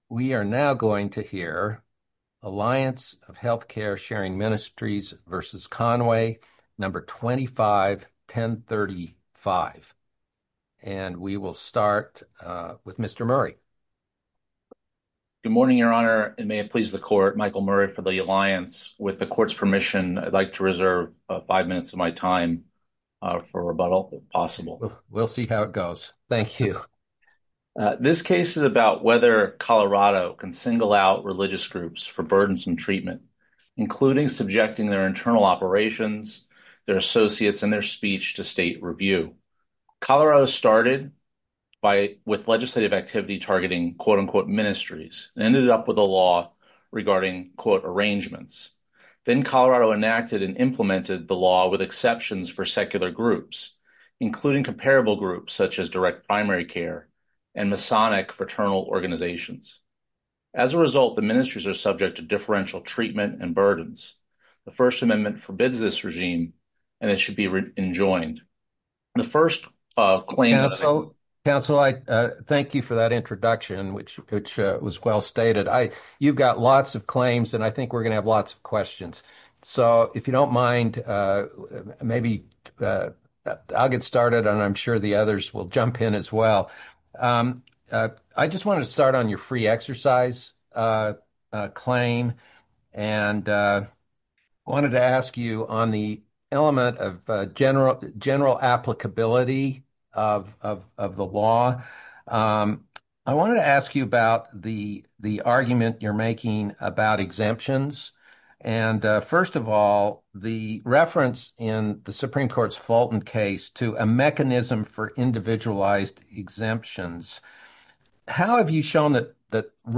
10th Circuit Hears Oral Arguments on Colorado's Regulation of Health Care Sharing Ministries